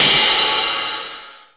The Roller Crash.WAV